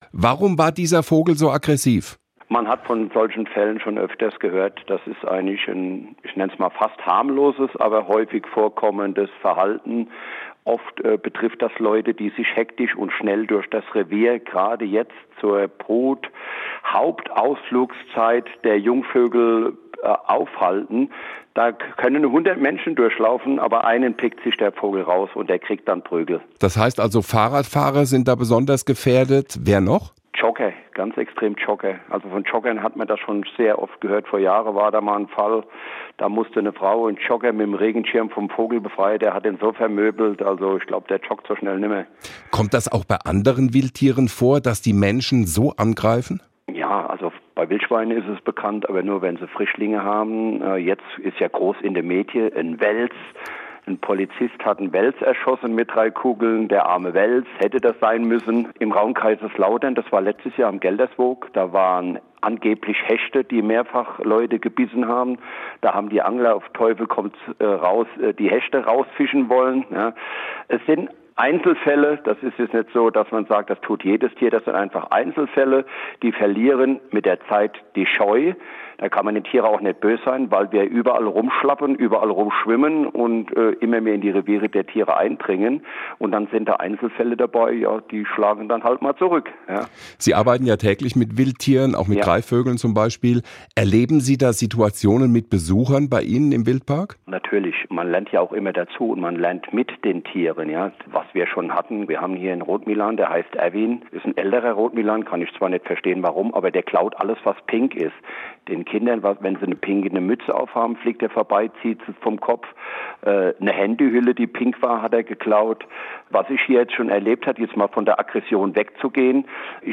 SWR1 Interviews